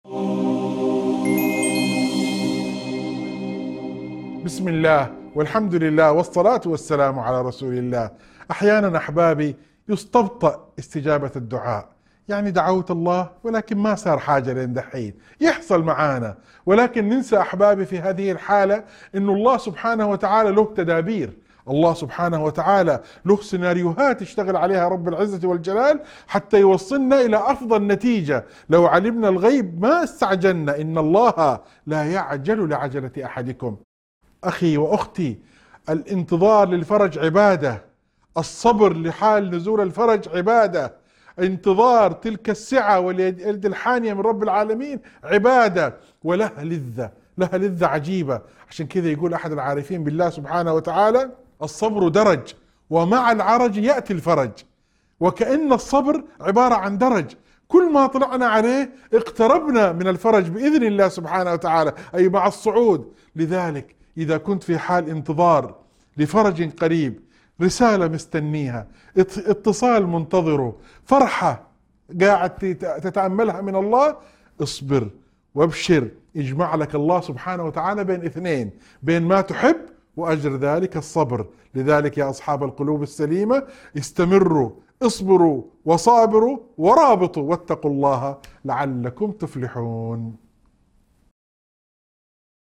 موعظة مؤثرة تشجع على الصبر وانتظار فرج الله، وتؤكد أن الانتظار نفسه عبادة يكافئ الله عليها. تذكر بأن الصبر درجات تقرب من الفرج، وتحث على حسن الظن بالله والتوكل عليه في كل الأحوال.